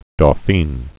(dô-fēn)